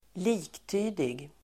Ladda ner uttalet
Uttal: [²l'i:kty:dig]
liktydig.mp3